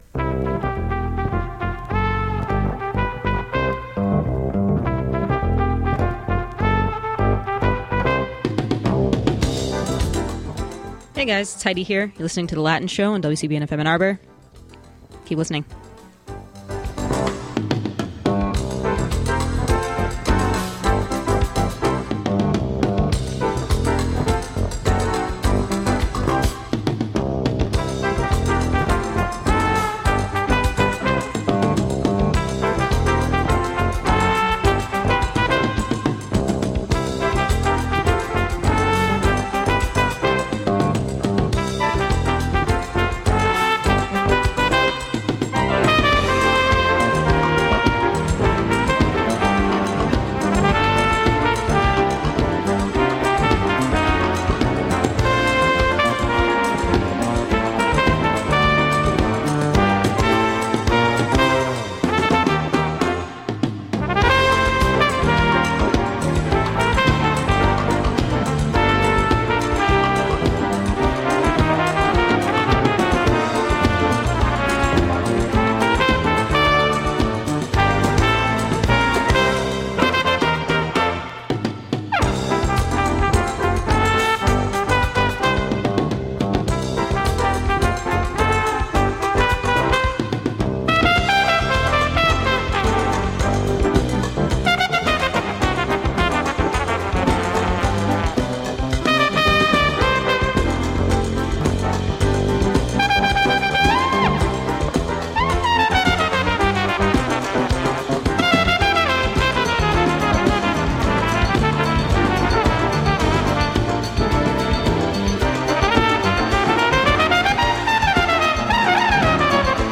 This was an especially lively show because there were a lot of people milling about: me, my friend who visited me at the station, and about 4 random guys in the lobby who I ran into.
I had a listener call in asking for something "cumbia."